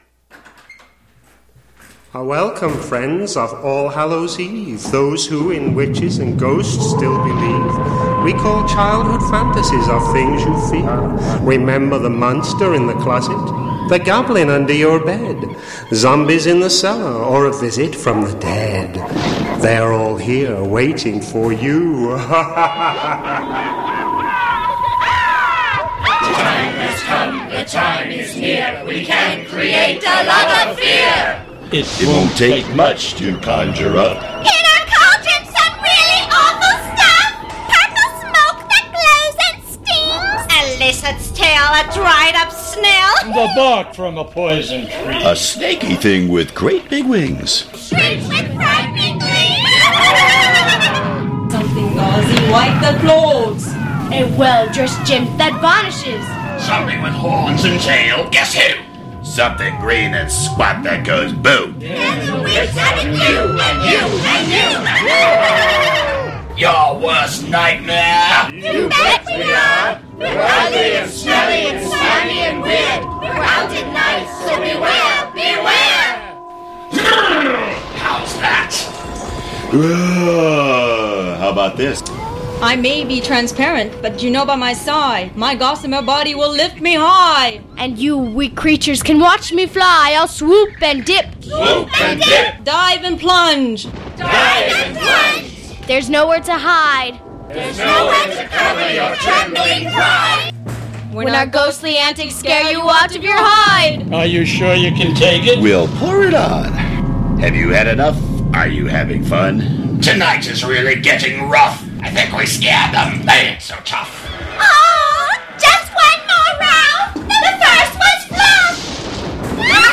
Tags: Voice Talent st.patrick saint patrick Voice actor